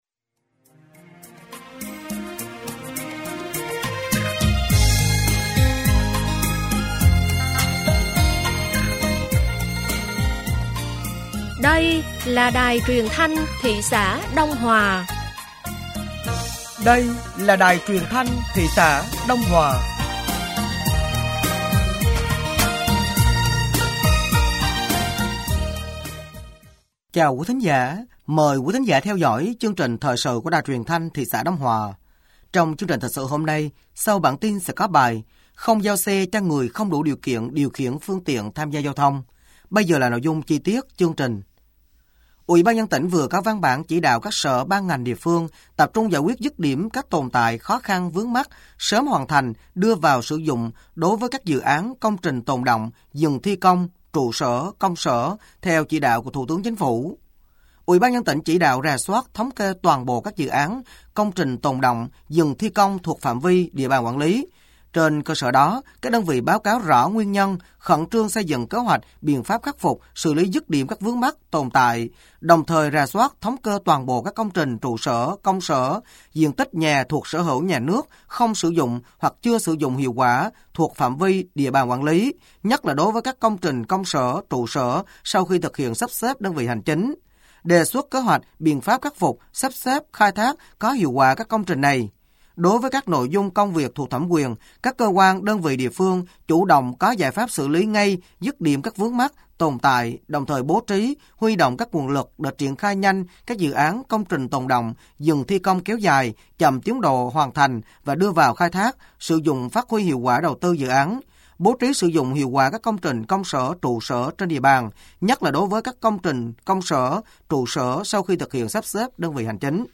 Thời sự tối ngày 23 và sáng ngày 24 tháng 11 năm 2024